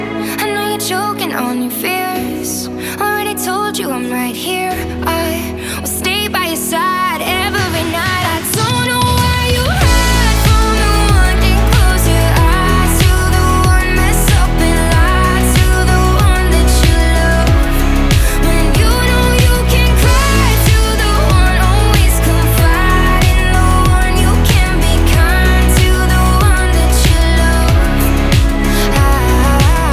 Genre: Dance